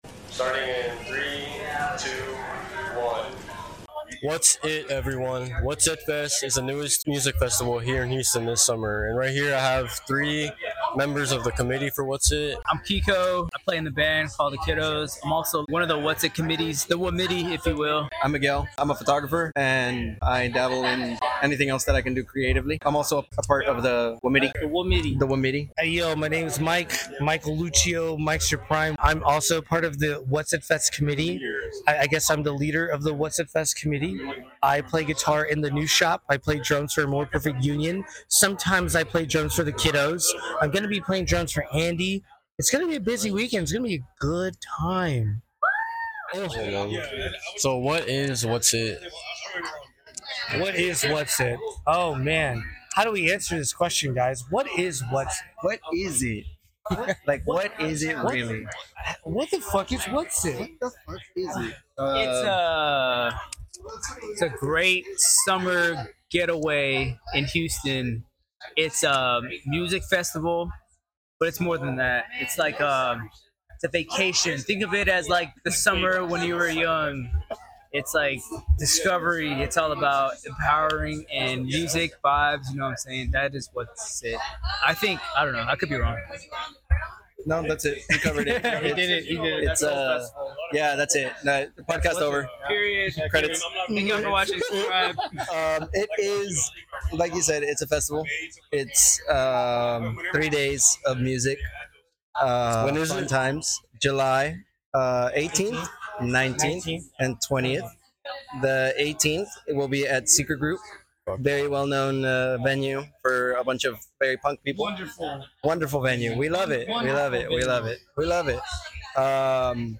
THE STREET COUCH #48 WHAT'S IT FEST COMMITTEE I interviewed 3 members of the WHAT'S IT FEST committee!